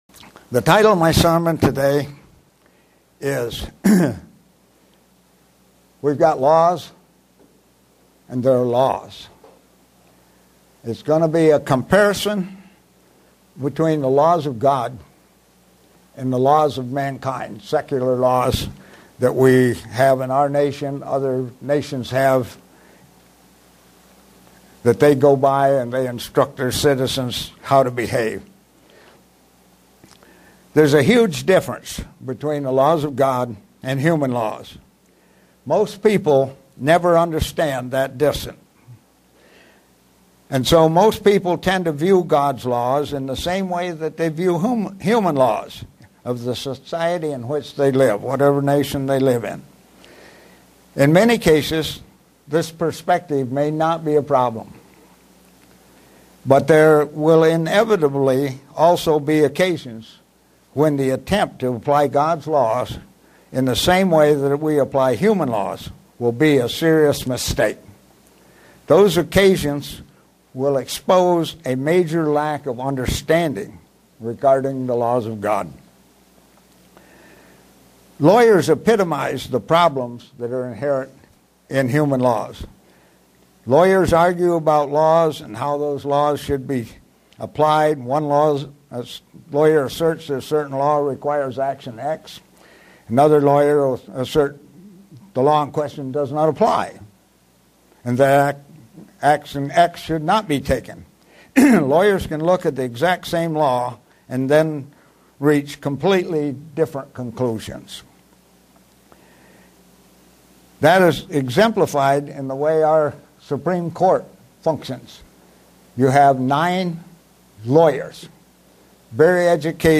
Given in Buffalo, NY
Man's Law doesn't hold to these principles. sermon Studying the bible?